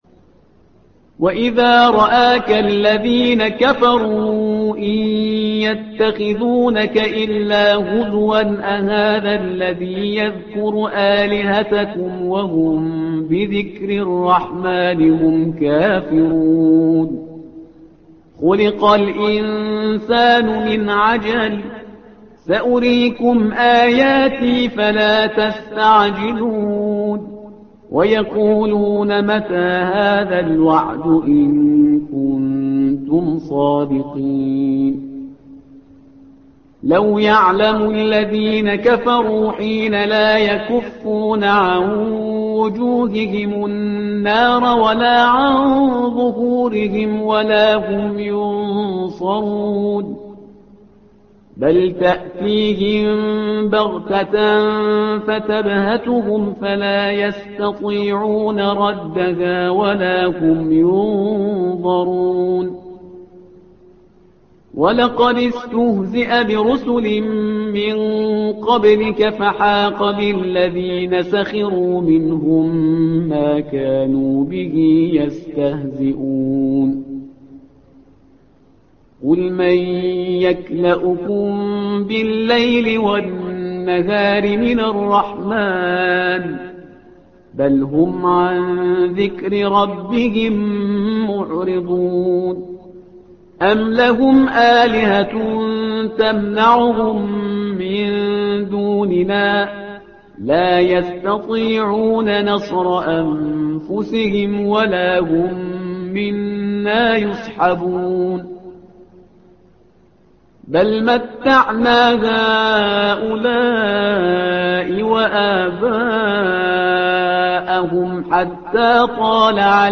القارئ